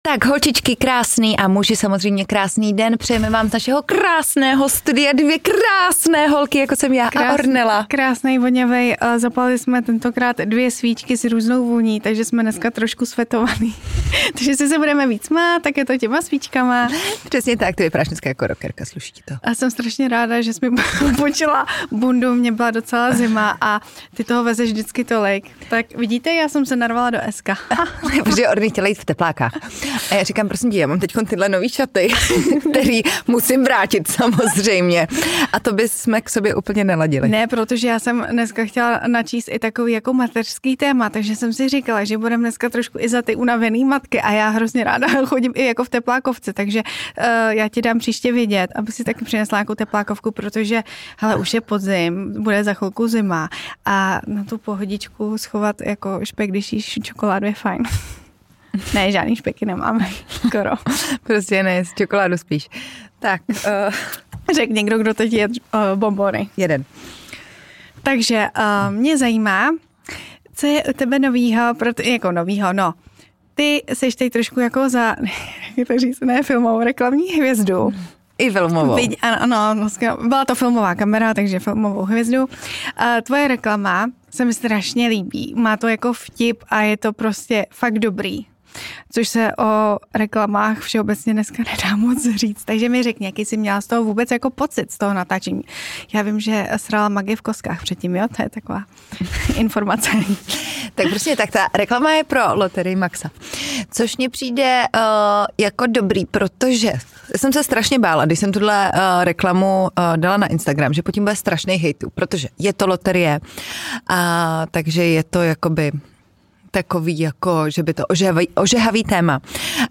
Tohle jsme prostě my a ten náš holčičí pokec o všem možném.